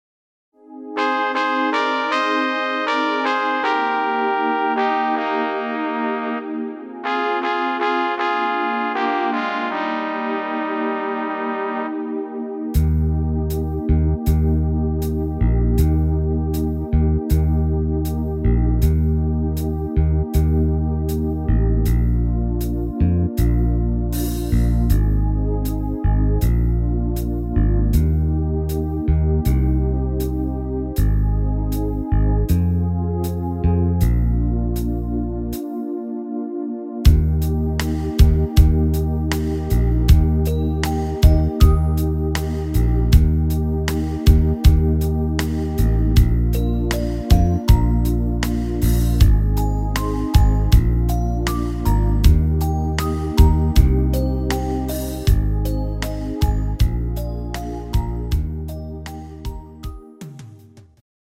instr. Panflöte